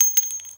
casingfall3.ogg